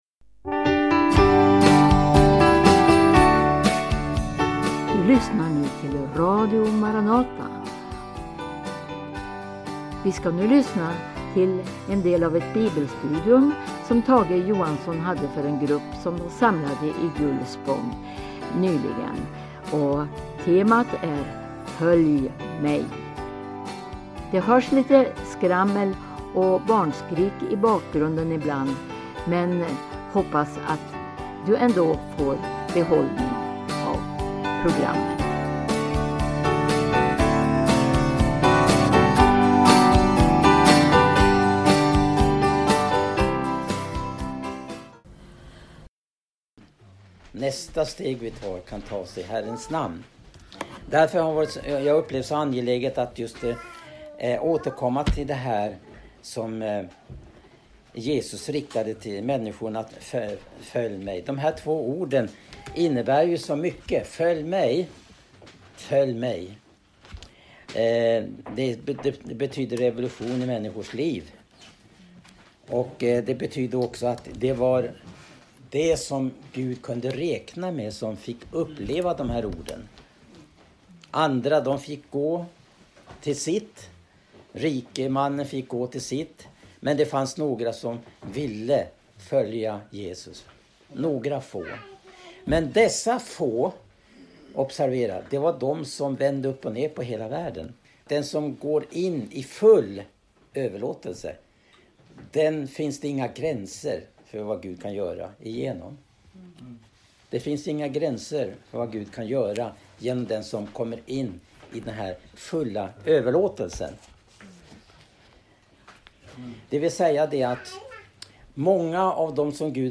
Bibelstudium
inspelat i Gullspång. Ämne: "Följ mig"